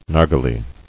[nar·ghi·le]